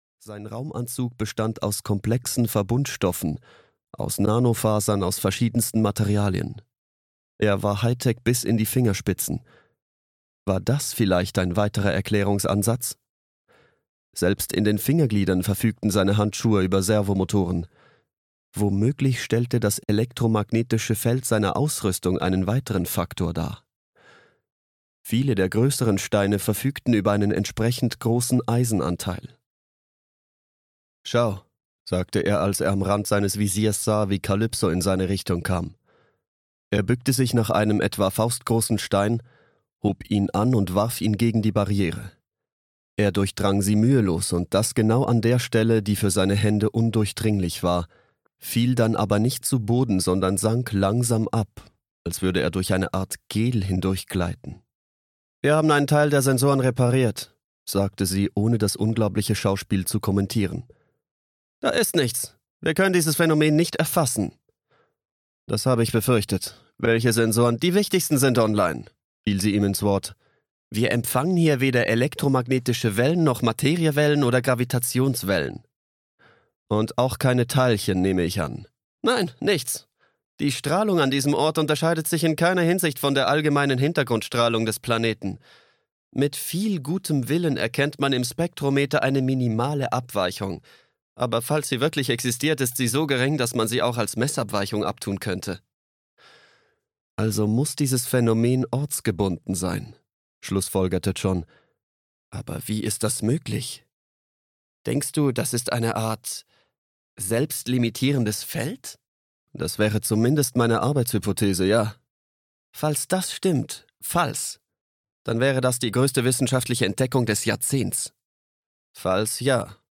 Rotes Grab (DE) audiokniha
Ukázka z knihy